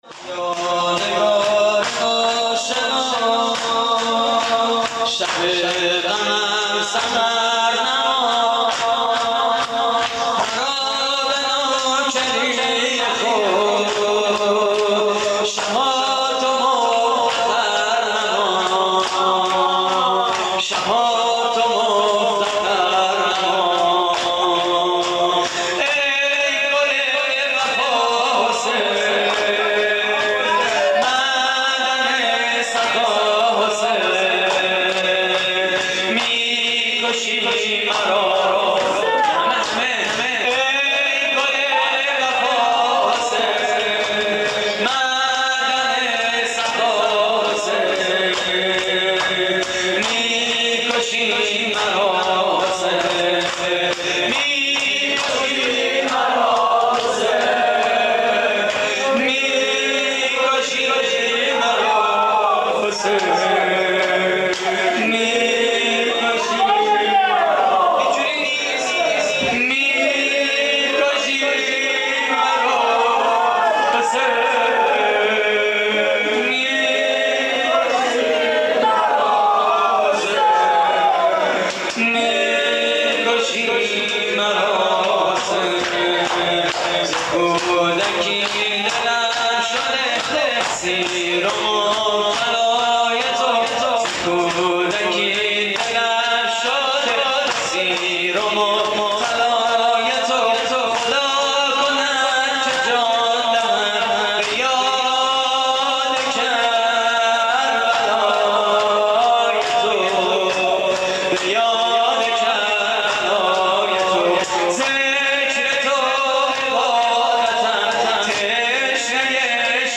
شورچهارضرب: بیا نگار آشنا
مراسم عزاداری اربعین حسینی / هیئت رایة الحسین (ع) - علی‌آباد